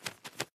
latest / assets / minecraft / sounds / mob / parrot / fly7.ogg
fly7.ogg